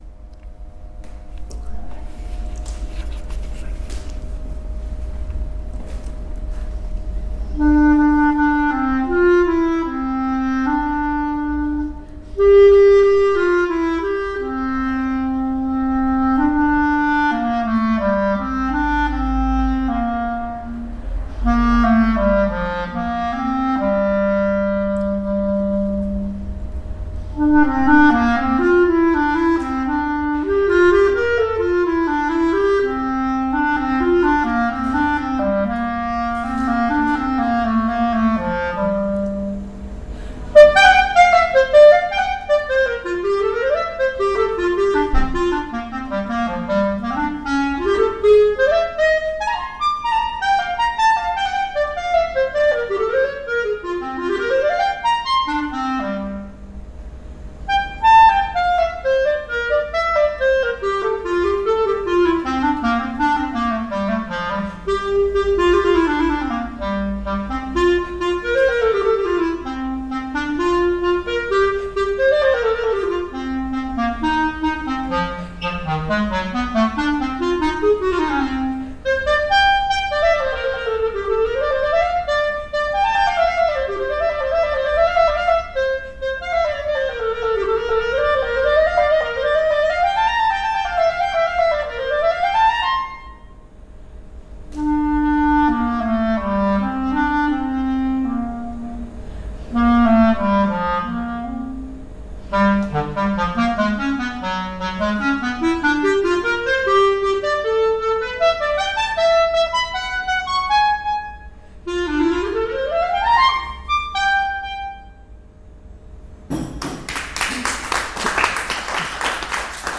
Autorské čtení a hraní k uzdravování
Zůčastnili jsme se autorského odpoledne v kavárně Moje Kavárna v areálu Thomayerovy nemocnice. Autorské čtení a hraní uspořádala skupina klecanských kamrádů z NÚDZ ve spolupráci se spolkem Lékořice.